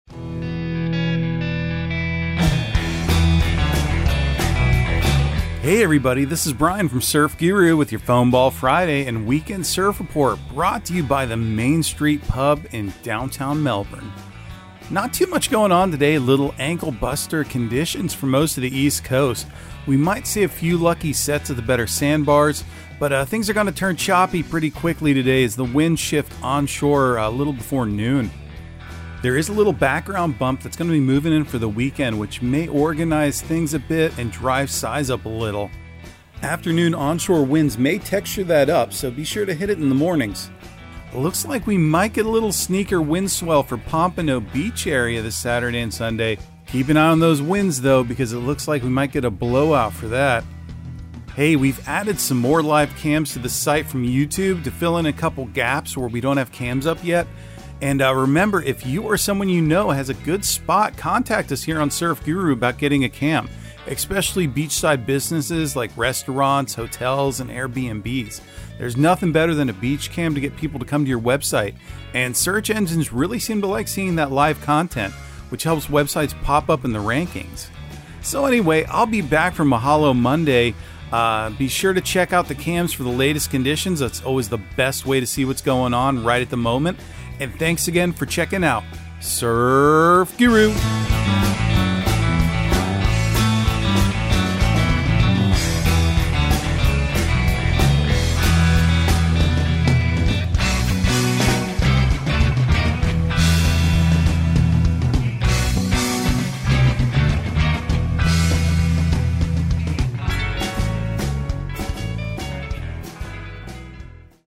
Surf Guru Surf Report and Forecast 10/14/2022 Audio surf report and surf forecast on October 14 for Central Florida and the Southeast.